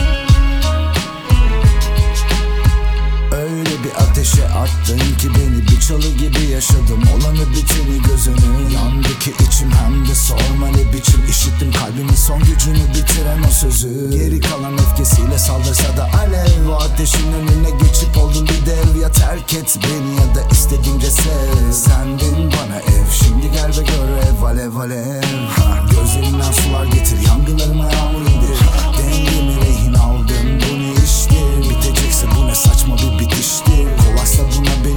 Turkish Hip-Hop Rap
Жанр: Хип-Хоп / Рэп